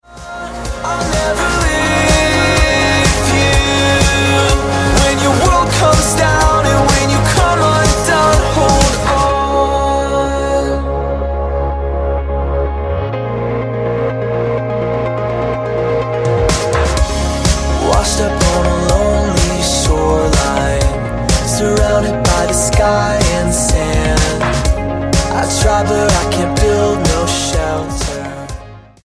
dance pop quintet
Style: Rock